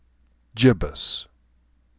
gĭbʹəs
GIB us